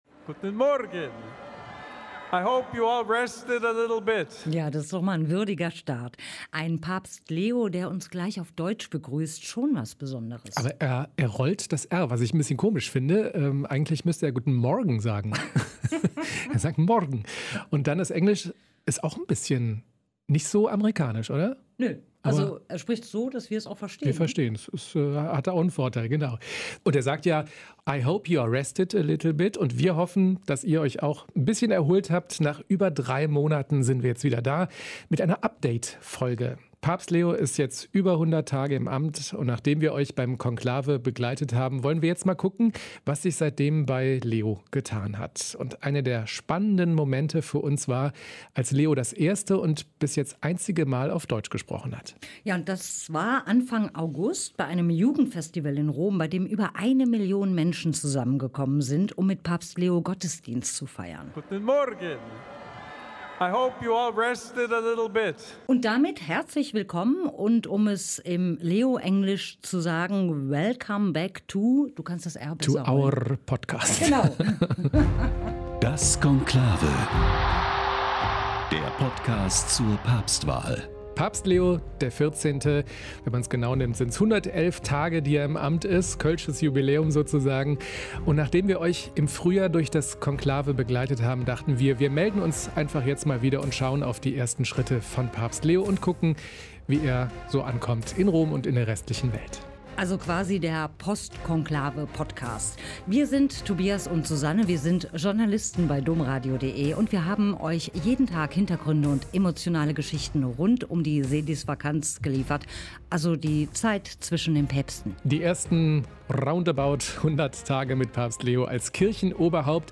Außerdem erklärt ein Augustinermönch, wie das WG-Leben unter Ordensleuten funktioniert